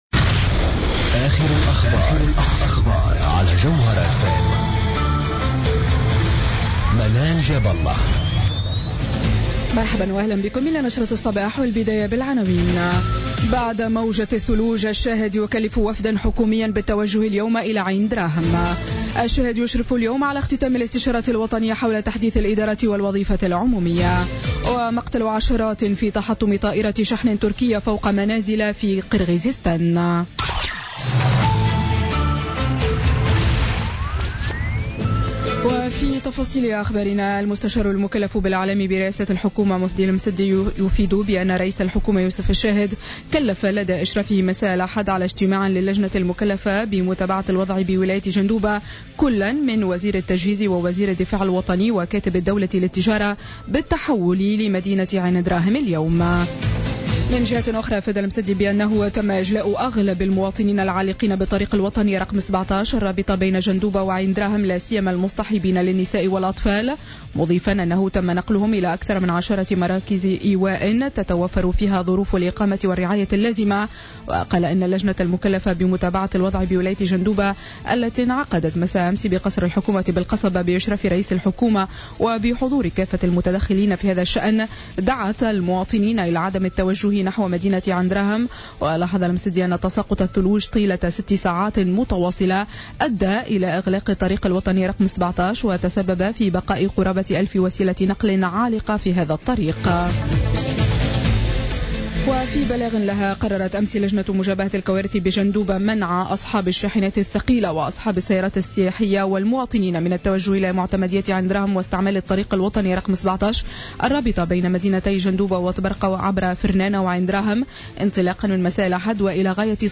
Journal Info 07h00 du lundi 16 Janvier 2017